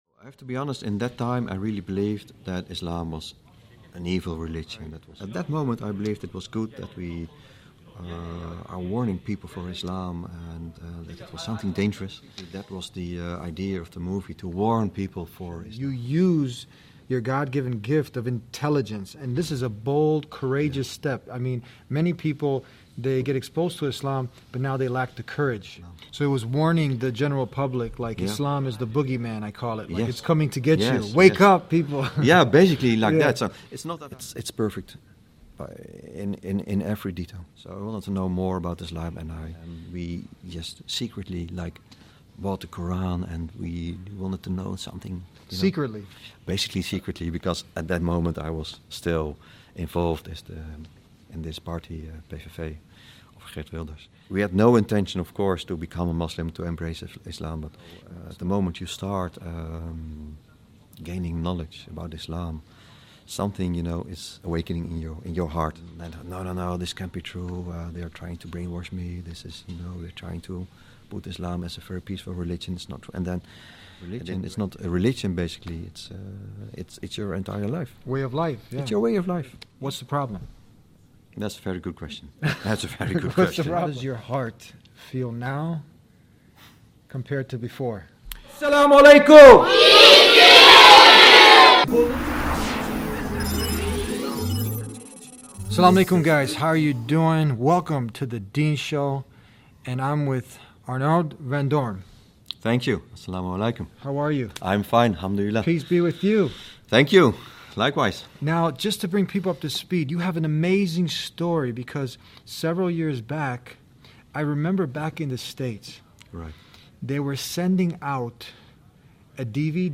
Through a candid conversation, Arnoud discusses his initial misconceptions about Islam, fueled by biases and misinformation, and how a genuine study and exploration of the faith led to his unexpected conversion. The episode highlights the common occurrence of Islam being depicted as a ‘boogieman’ in mainstream media and urges viewers to seek authentic sources of information to gain a true understanding of the religion.